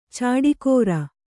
♪ cāḍikōra